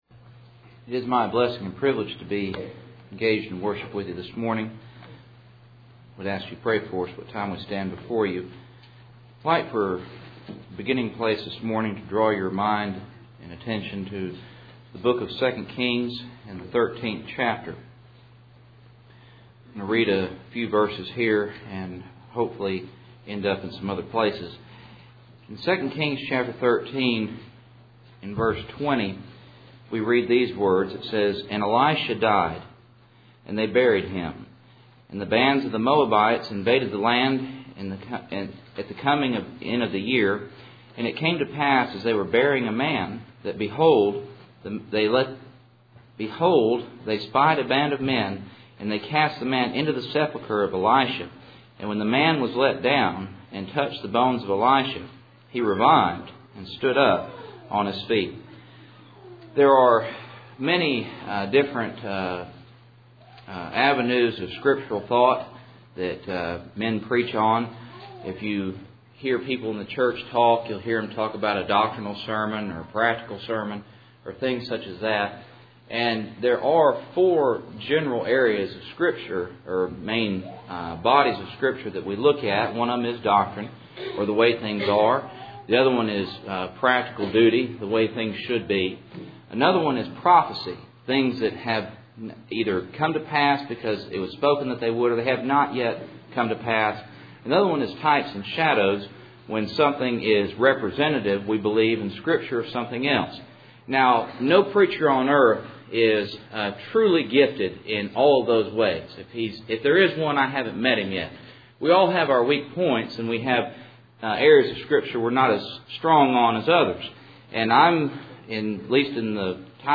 Cool Springs PBC Sunday Morning